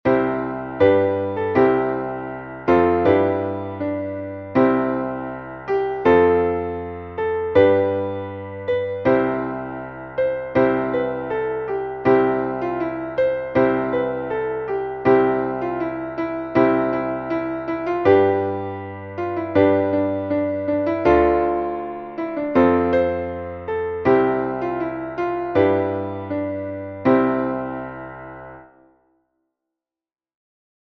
Traditional Christmas carol